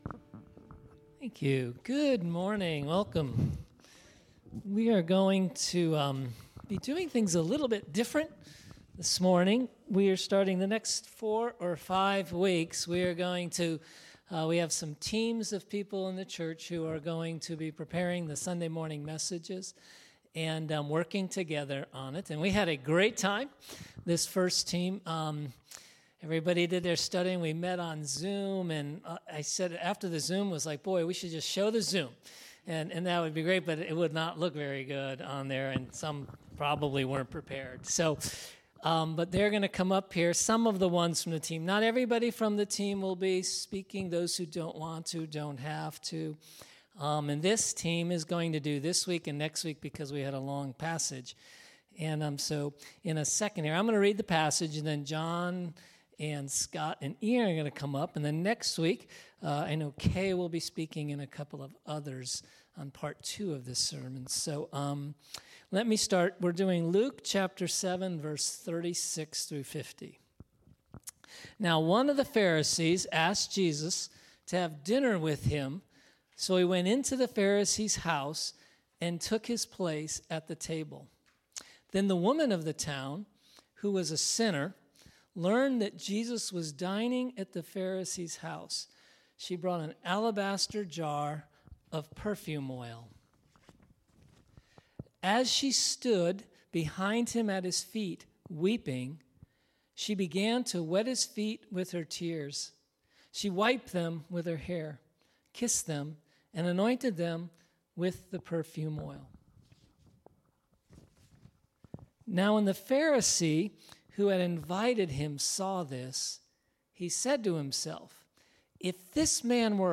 We are going to be doing some ‘team teaching’ – we went over this on Zoom and it was so good I wish we could just show you the Zoom – but we trust it will be even better in person!